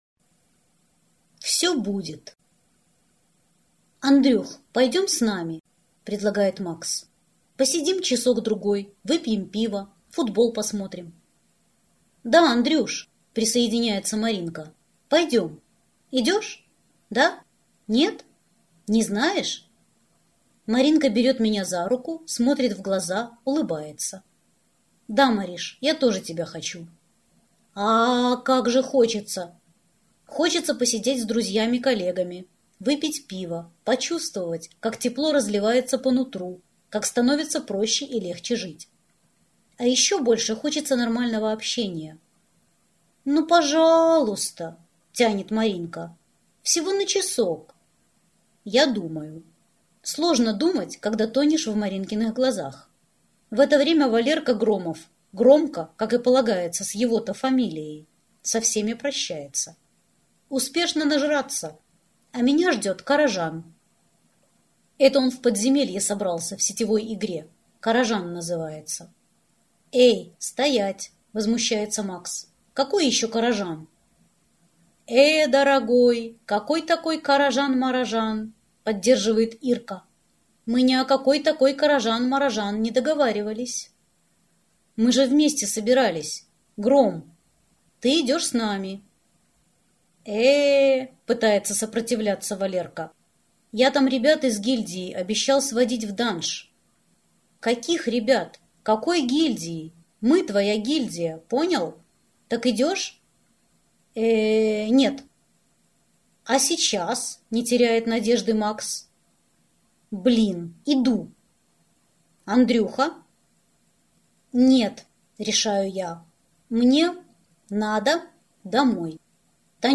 Аудиокнига Буквы (сборник) | Библиотека аудиокниг